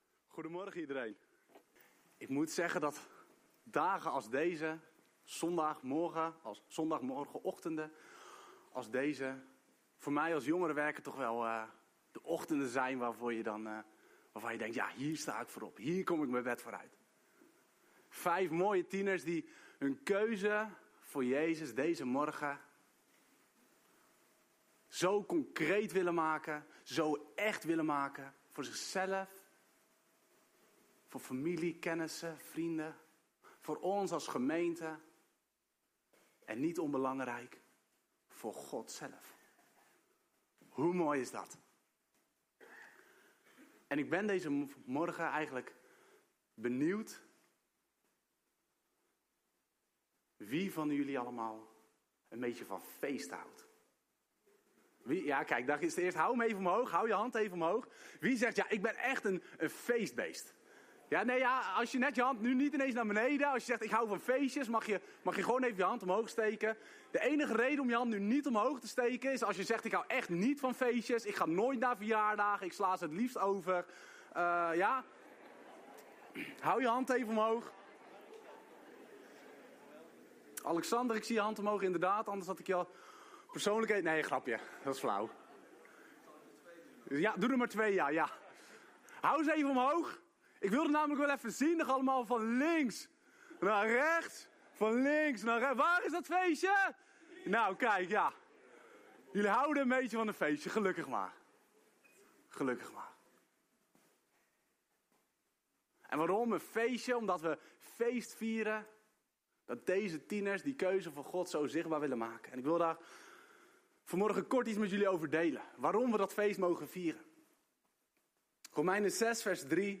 Doopdienst